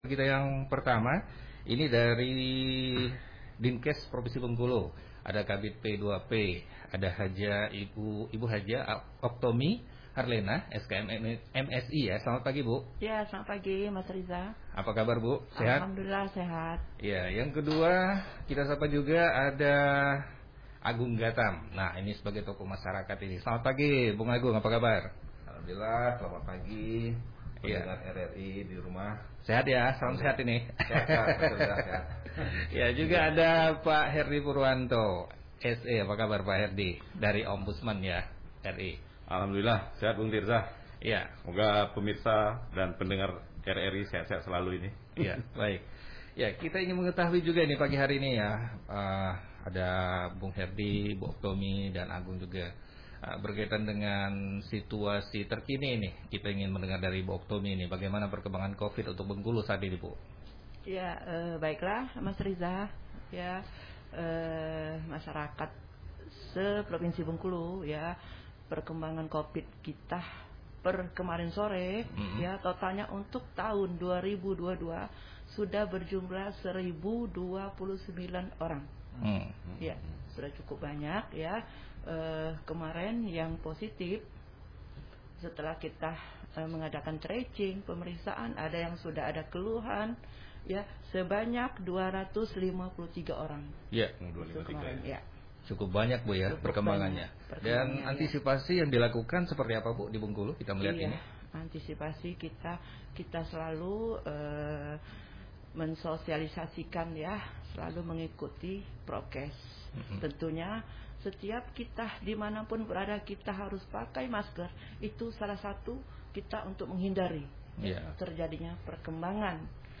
dialog_lintas_bengkulu_menyapa_edisi_kamis_17_februari_2022.mp3